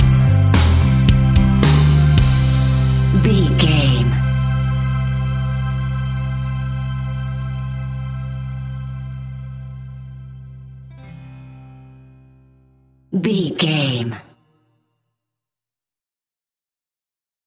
Ionian/Major
calm
melancholic
energetic
smooth
uplifting
electric guitar
bass guitar
drums
pop rock
indie pop
instrumentals
organ